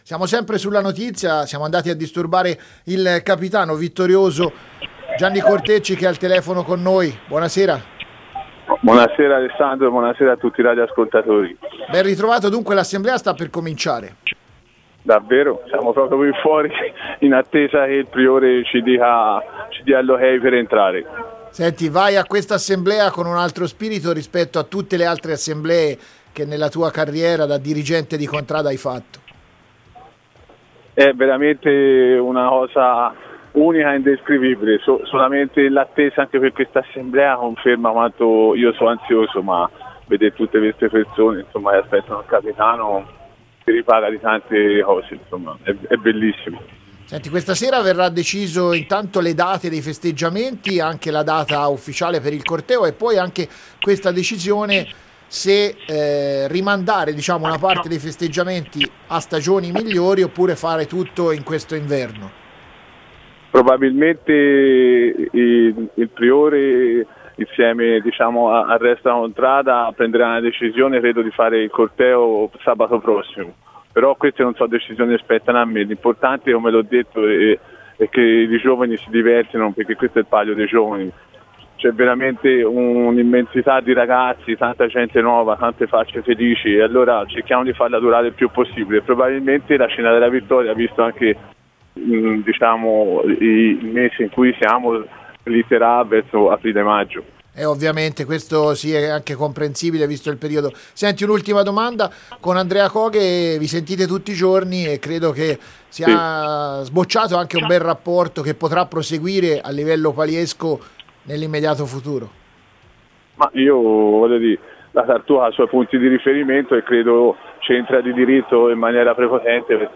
Interviste
In diretta ad Are